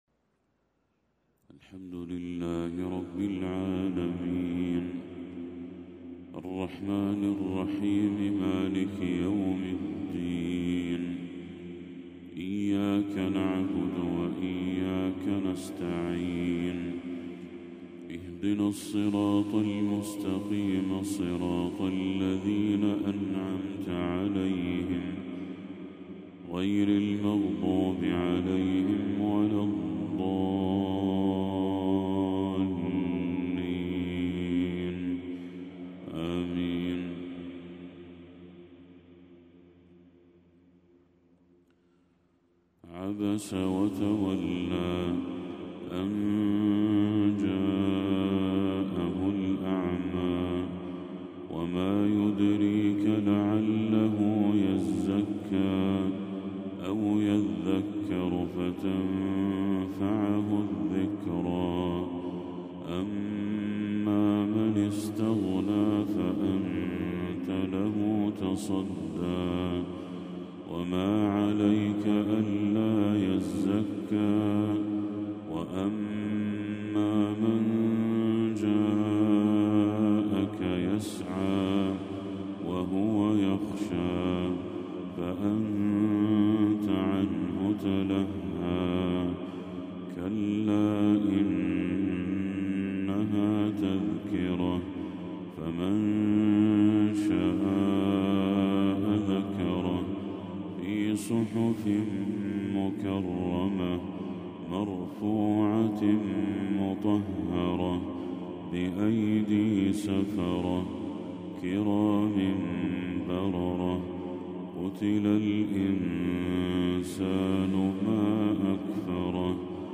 تلاوة لسورتي عبس والبلد للشيخ بدر التركي | فجر 25 ربيع الأول 1446هـ > 1446هـ > تلاوات الشيخ بدر التركي > المزيد - تلاوات الحرمين